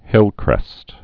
(hĭlkrĕst)